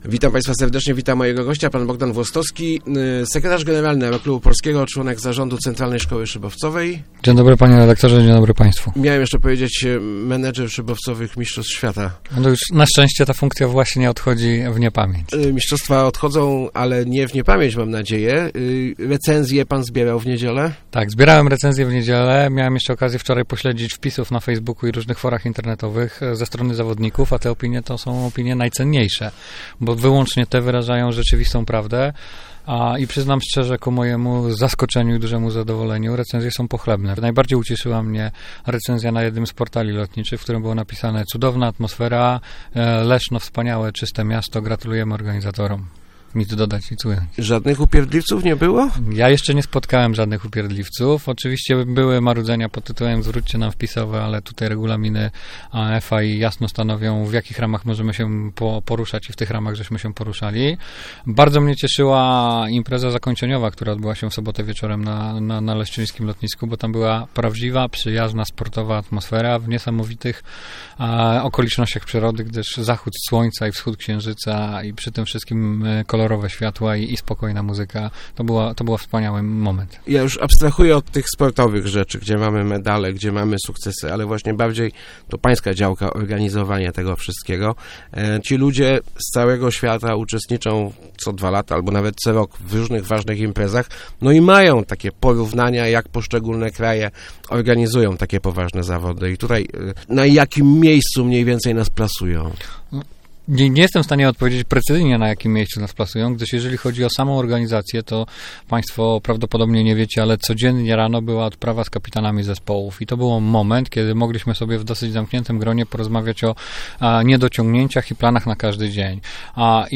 Leszno, Portal Regionu, regionalne, lokalne, radio, elka, Kościan, Gostyń, Góra, Rawicz, Wschowa, żużel, speedway, leszczyński, radio, gazeta, dodatek